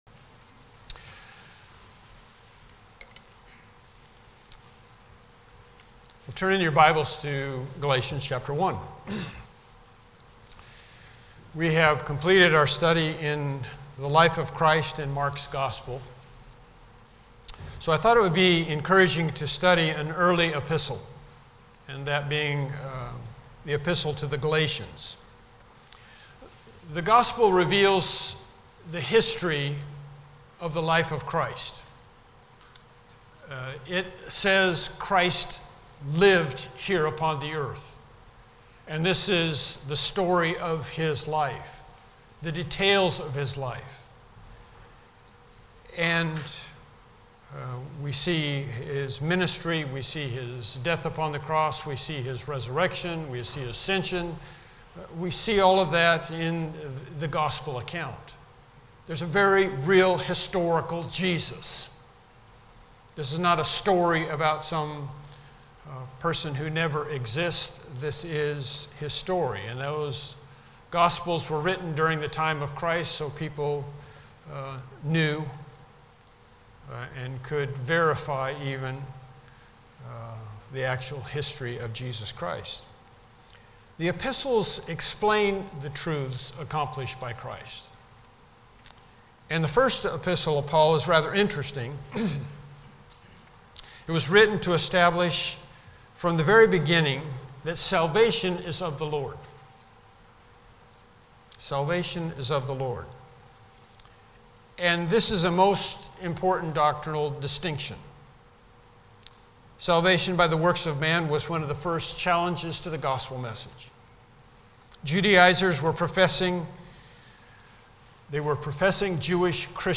Galatians Passage: Galatians 1:1-5 Service Type: Morning Worship Service « Lesson 12